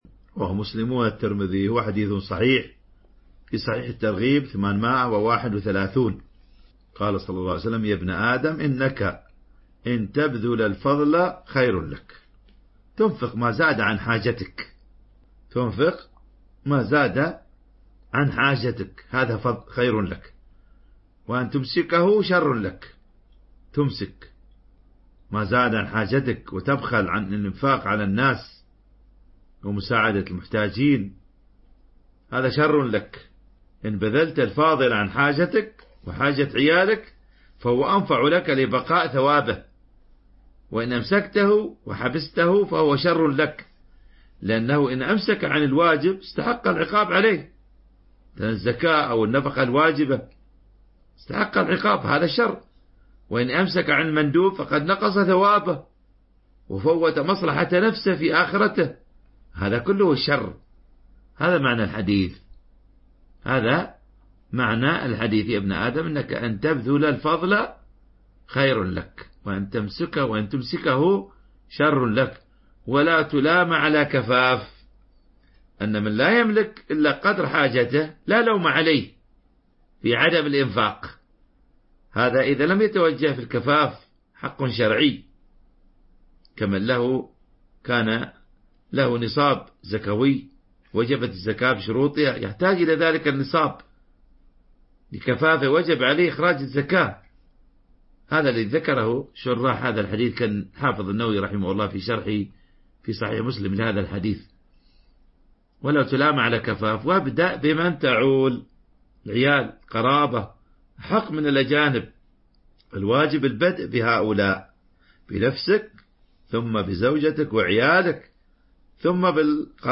شرح حديث يا ابن أدم إنك إن تبذل الفضل خير لك